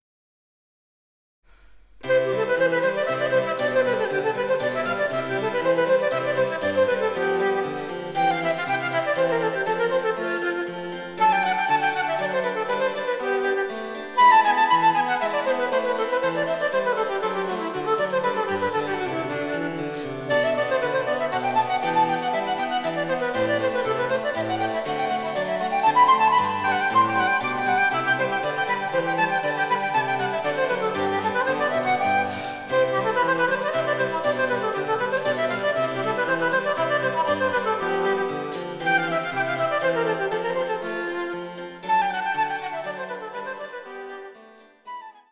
アンダンテの部分は係留音が美しく響いて実にモダンな雰囲気を持っています。
■フルートによる演奏
チェンバロ（電子楽器）